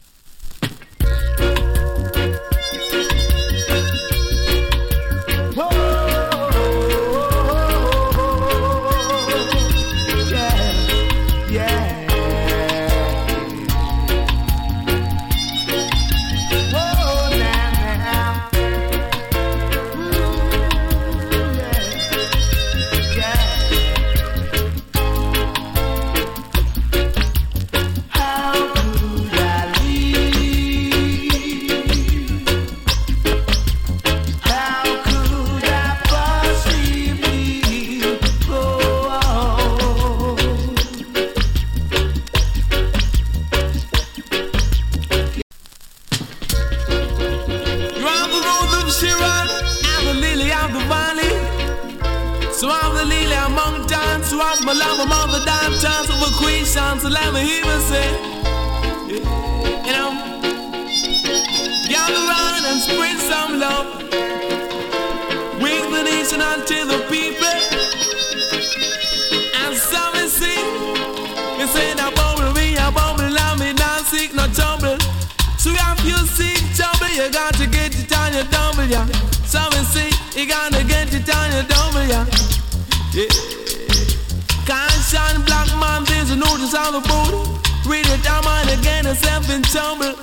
A：VG(OK) / B：VG(OK) ＊スリキズ有り。チリ、パチノイズ少々有り。ジリノイズ全体的に少し有り。
COVER ! ＋ DUB !! FLIP SIDE は DEE-JAY CUT !